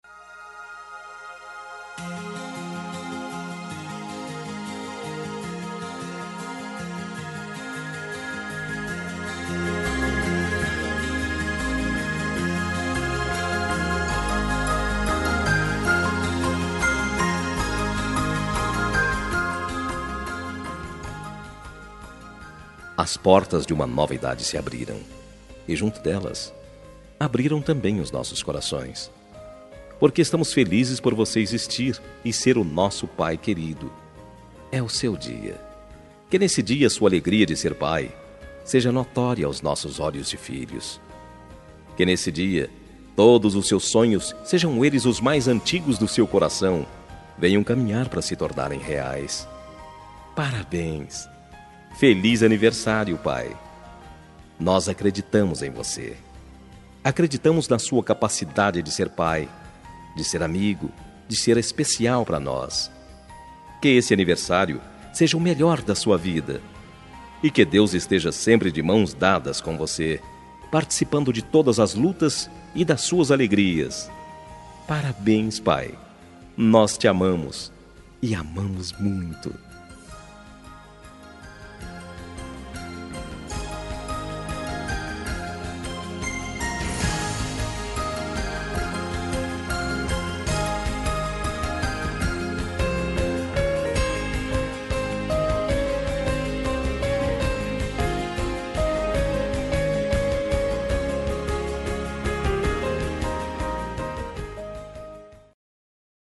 Aniversário de Pai – Voz Masculina – Cód: 11601